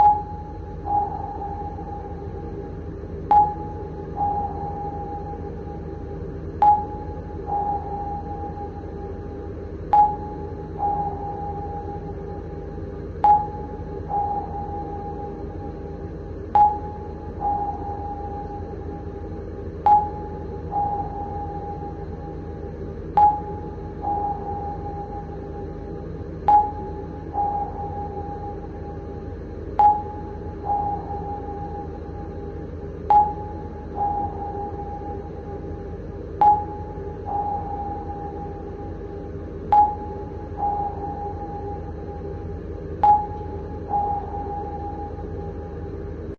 潜水艇
描述：在Logic中捣鼓了一些环绕脉冲响应和延迟设计。 最后得到的东西听起来有点像潜水艇声纳的爆音。所以我加入了冰箱的声音，用flex工具拉伸了时间，调整了音高，现在听起来几乎是真的:)
Tag: 潜水艇回波 回声定位 潜艇 声纳